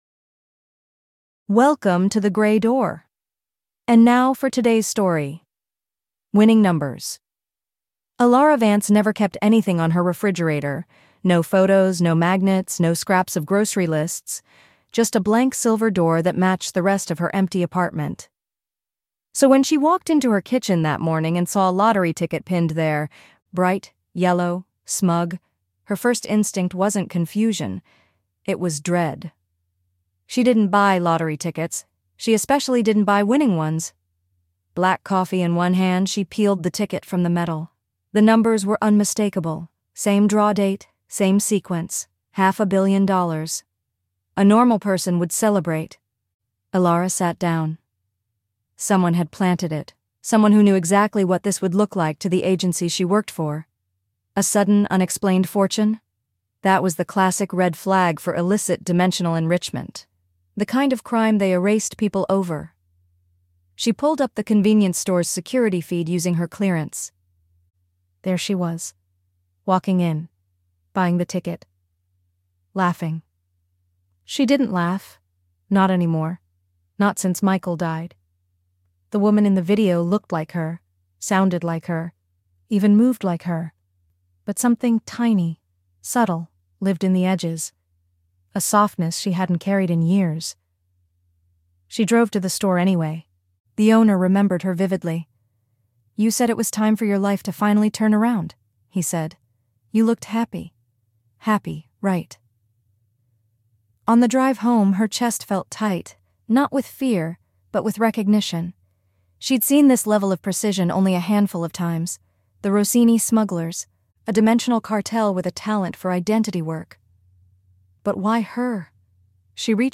The Gray Door is a storytelling podcast